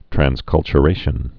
(trănskŭl-chə-rāshən)